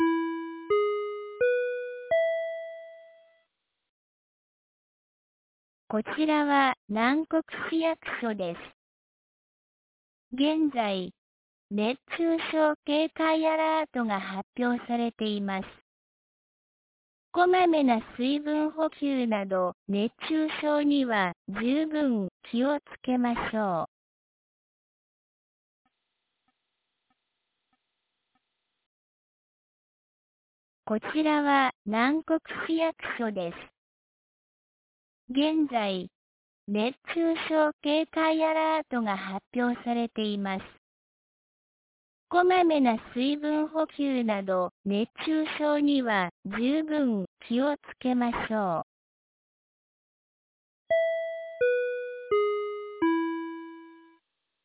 2025年07月02日 09時00分に、南国市より放送がありました。